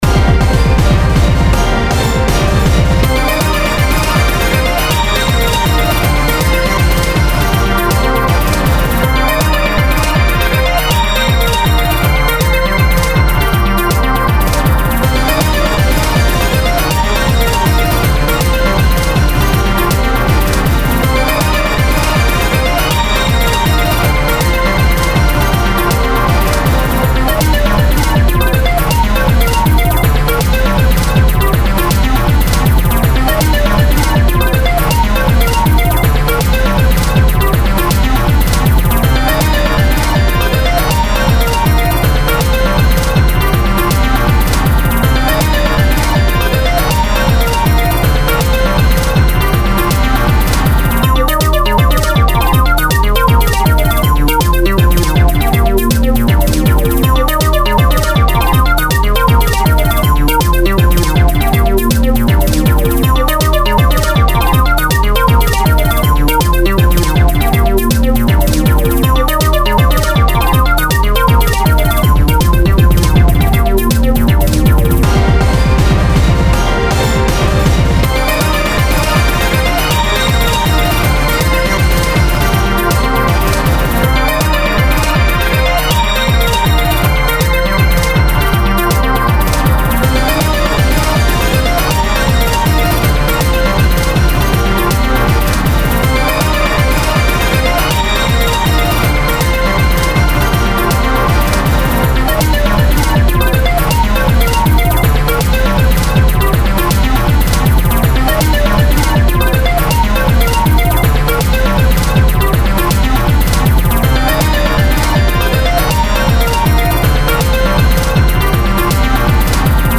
Game Music
boss-battle